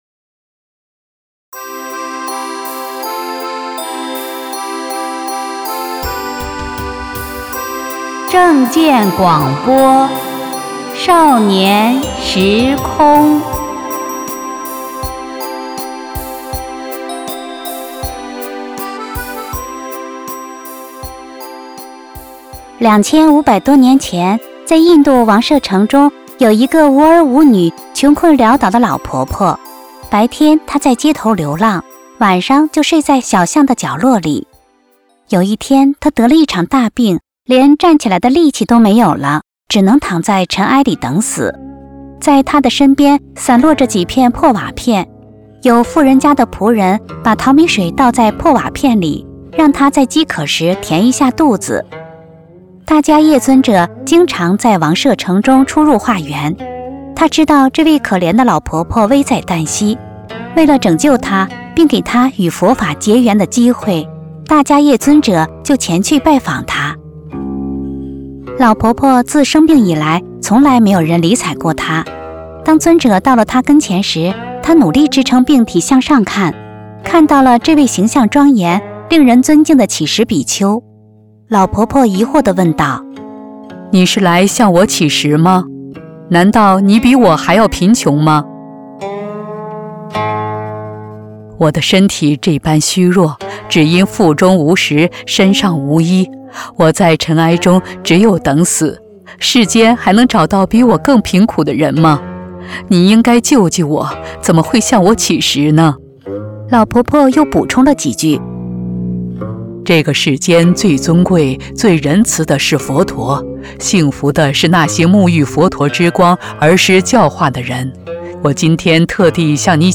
女聲獨唱